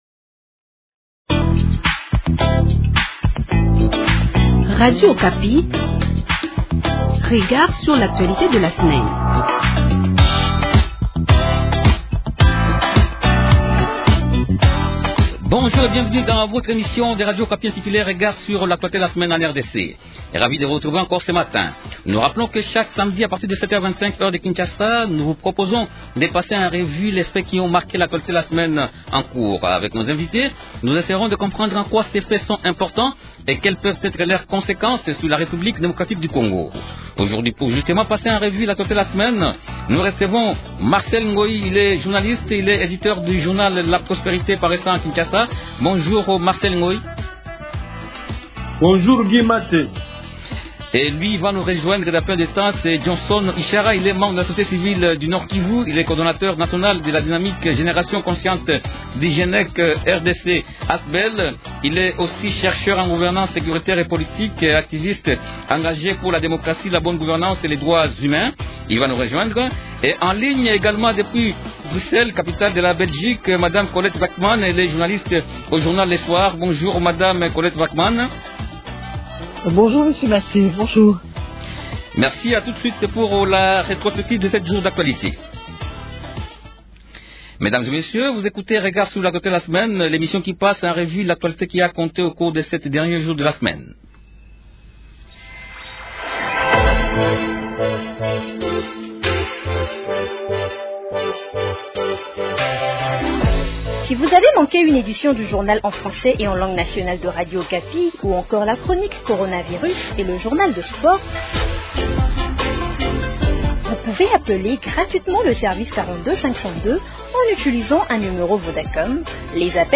Trois sujets principaux au menu de cette émission :